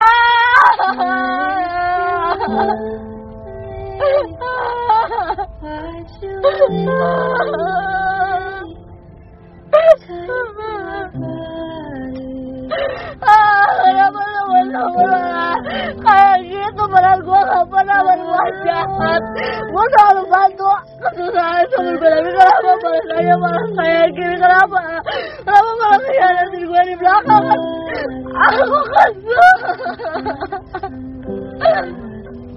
Suara tangisan Sedih
Kategori: Suara manusia
Keterangan: Suara tangisan sedih dari wanita mp3 untuk diunduh. Rekaman ini menampilkan ekspresi emosional, cocok untuk kebutuhan audio dalam produksi konten atau efek suara.
suara-tangisan-sedih-id-www_tiengdong_com.mp3